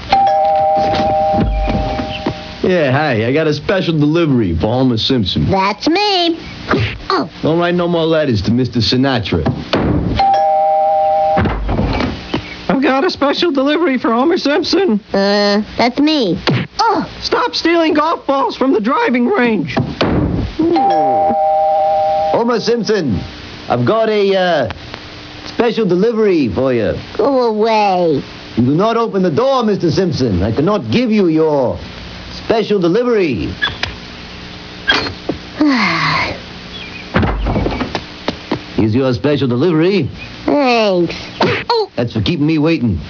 Bart answer the door for Homer and getting punched 3 times!